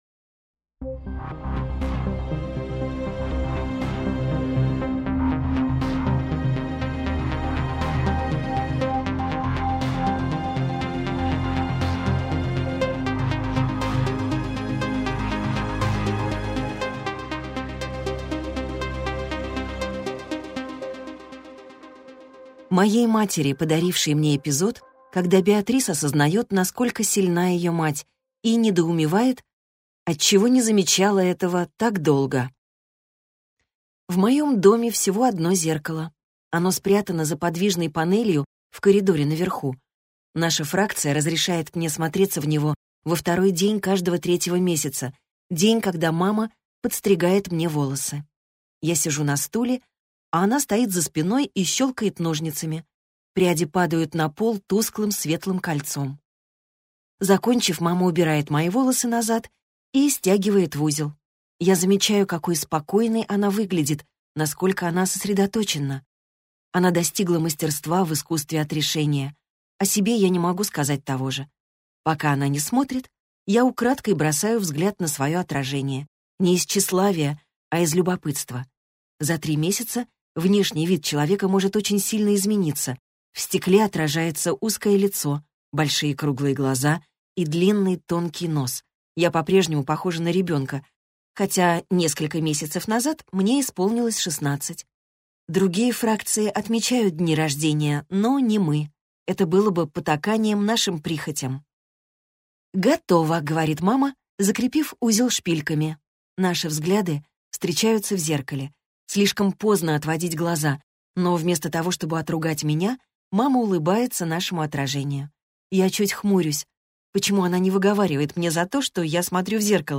Аудиокнига Дивергент - купить, скачать и слушать онлайн | КнигоПоиск